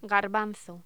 Locución: Garbanzo